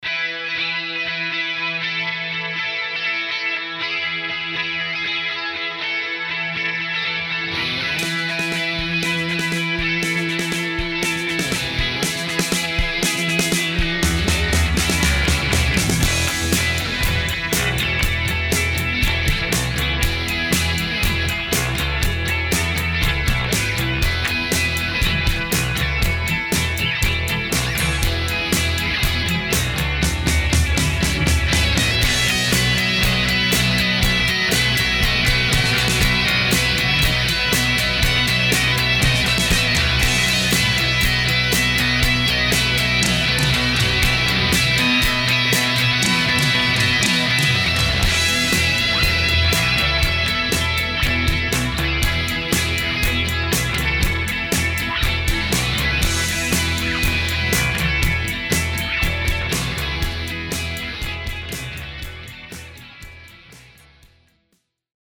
General Pop Tracks, Guitar pop tracks